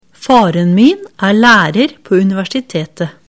setningsdiktat_skolesystemet03.mp3